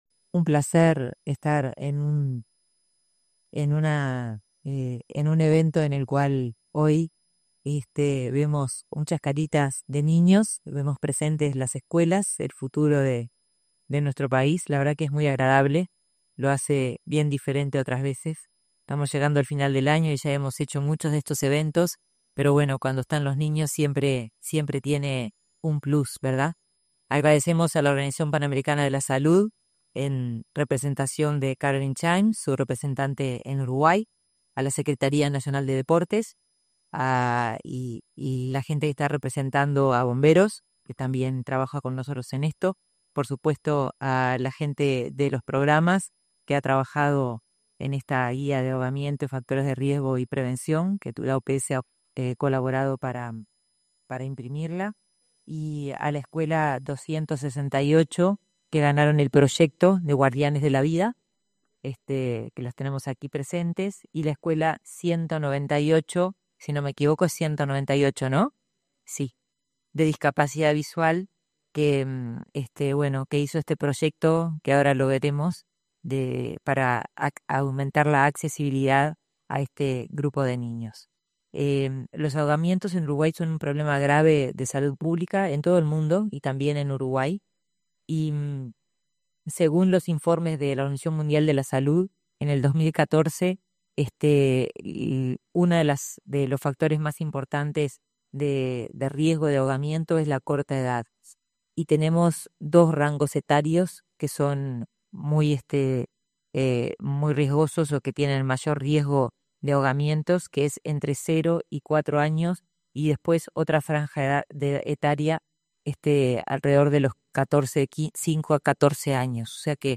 Palabras de la ministra de Salud Pública, Karina Rando